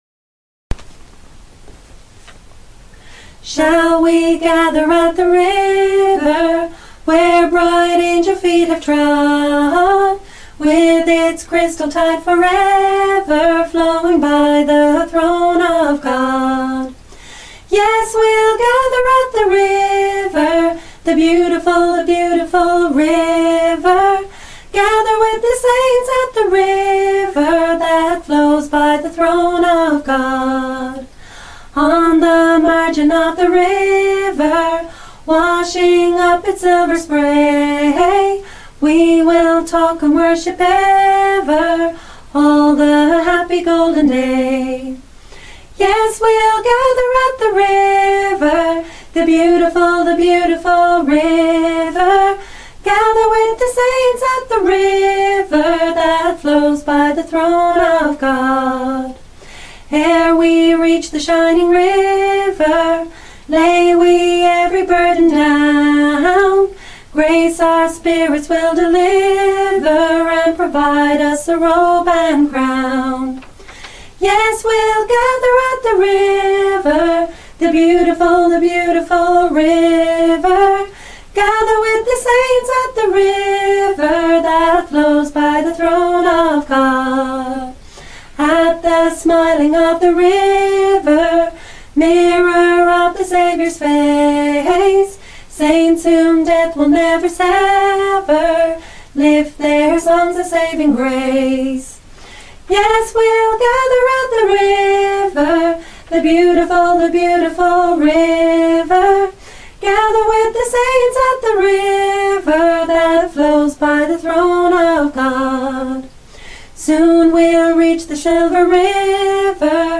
Shall We Gather At The River Join our singalong of praise.
We love to praise and join in singalong praises together & lift our hearts to be rejoicing folks like this Celtic   MMM Choir.